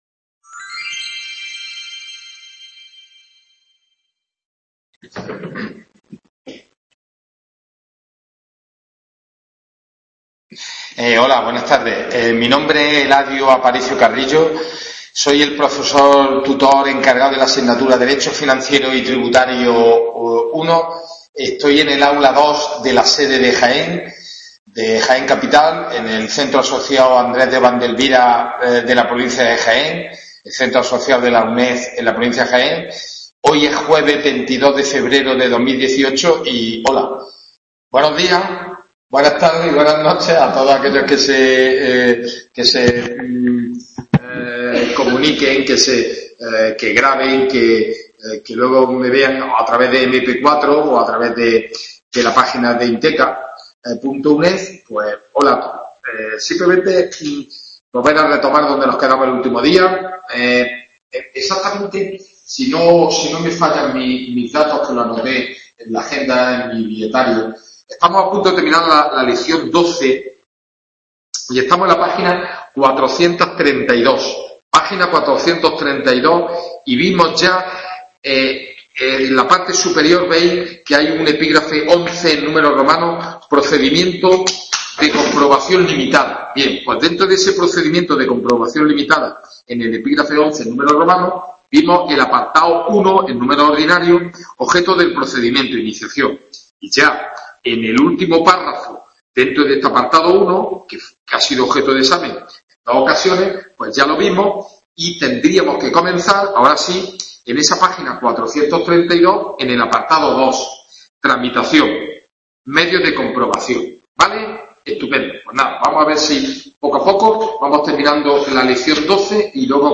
WEB CONFERENCIA